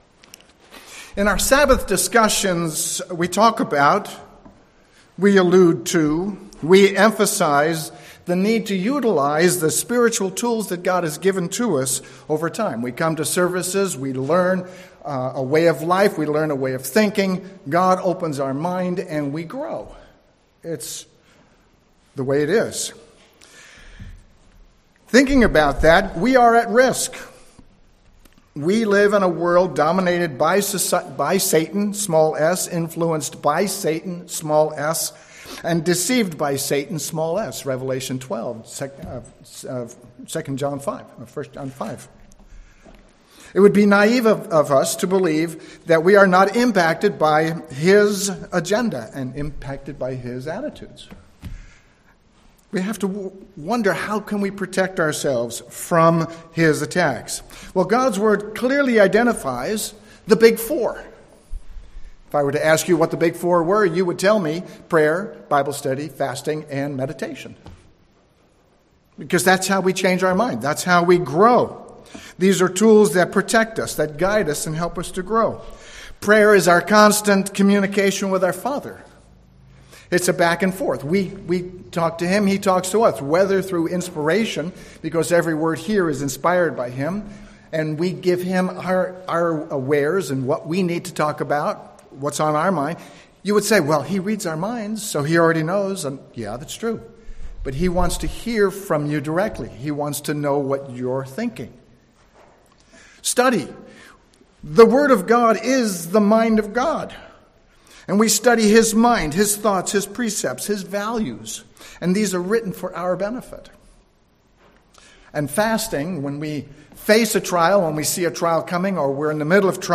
Listen to this sermon to learn why engaging in the right kind of meditation is so important, and how reflecting and internalizing God’s way of life is achieved with meditation. A quiet mind can hear God’s instruction, and meditation is a powerful tool to help us better understand His plans and thoughts.